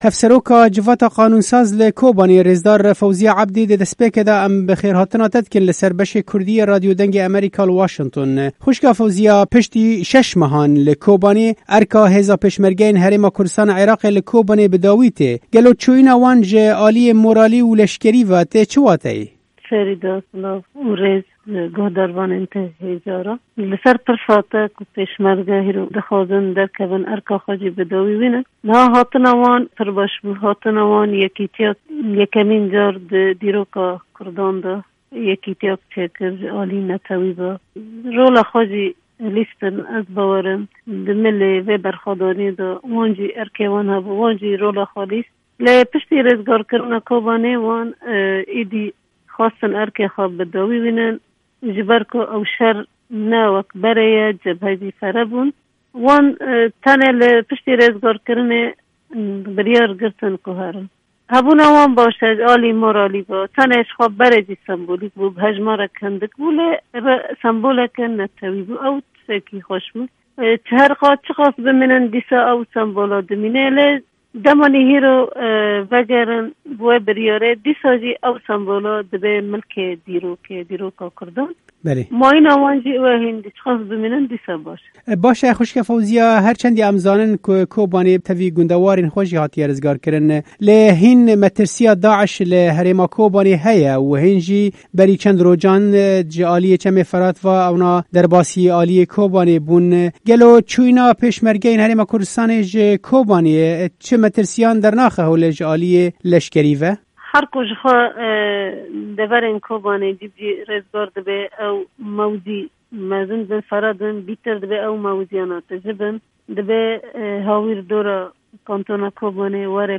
Hevseroka Civata Qanûnsaz li bajarê Kobaniyê rêzdar Fewziya Ebdî, ji Dengê Amerîka re ev babet șîrove kir û got: